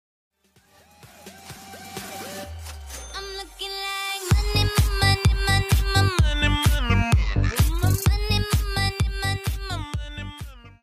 twitch-alert-sound-1-donation-sound-money-audiotrimmer.mp3